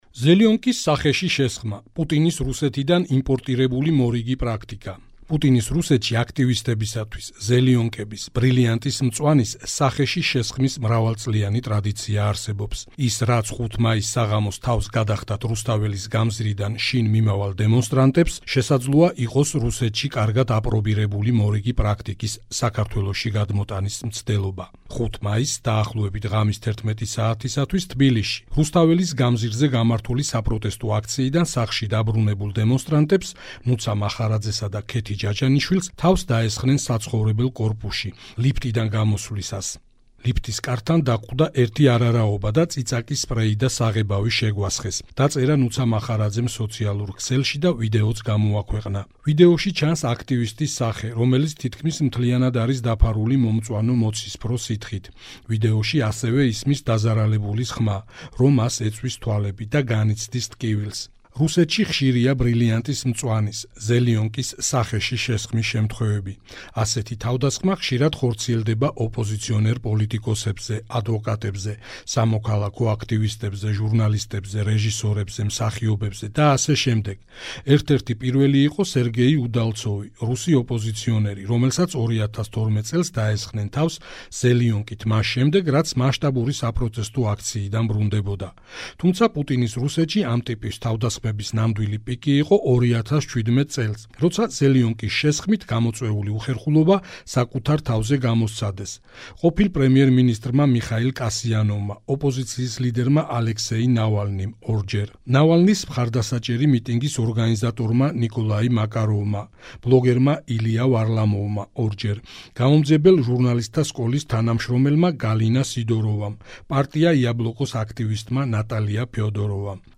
მოუსმინე სტატიას - „ზელიონკის“ სახეში შესხმა“